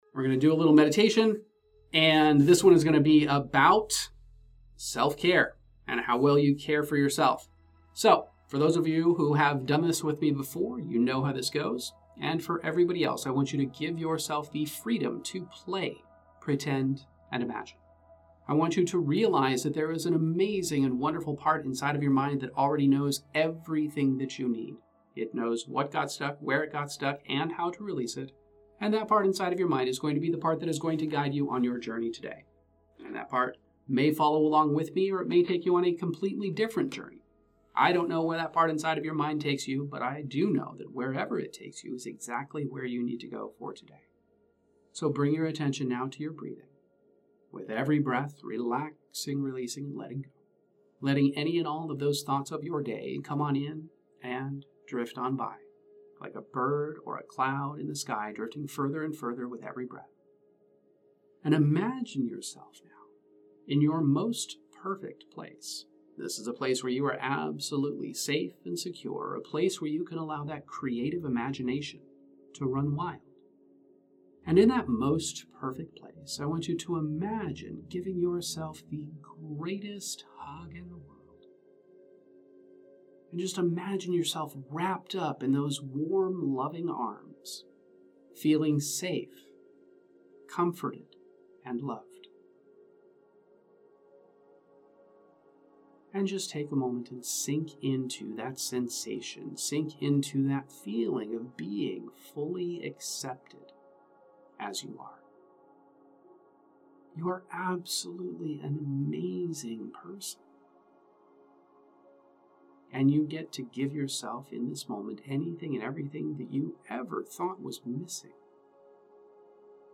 This guided hypnosis meditation is a deep dive into self-care, guiding you from feeling all closed off to embracing love and connection in a big way. It starts with you chilling out and imagining the ultimate safe space where you can let your imagination run wild. Picture giving yourself the world's biggest hug, feeling totally accepted and loved, just as you are.